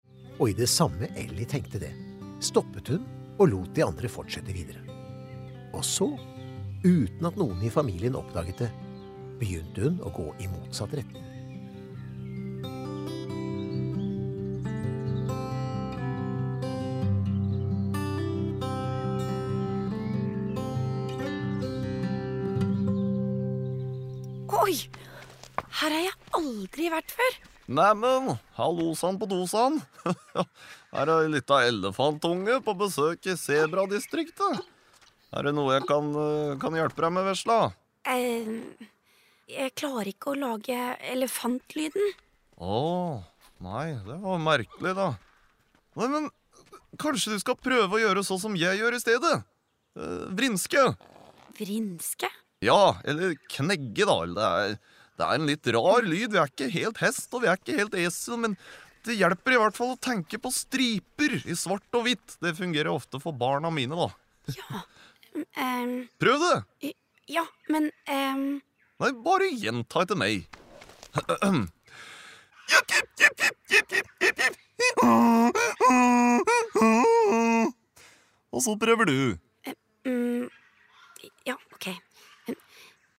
Nedlastbar lydbok
En koselig historie med sang og musikk, om å finne sin egen stemme, men også om å finne sin plass i flokken.